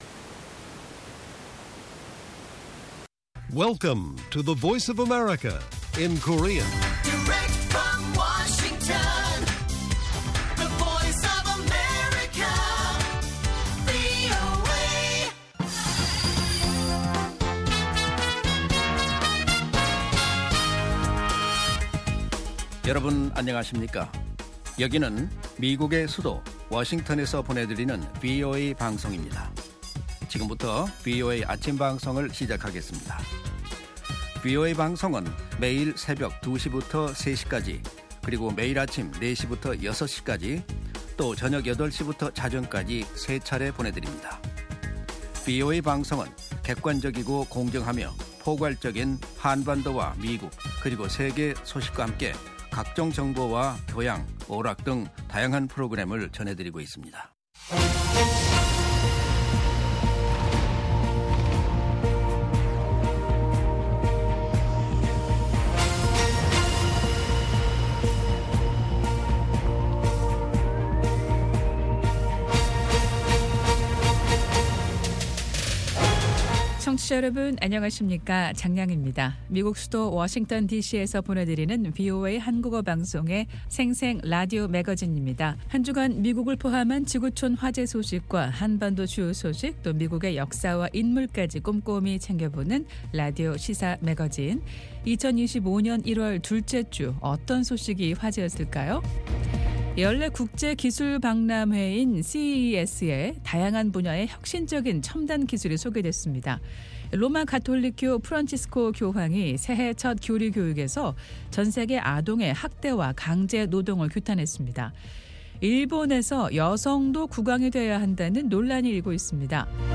VOA 한국어 방송의 월요일 오전 프로그램 1부입니다.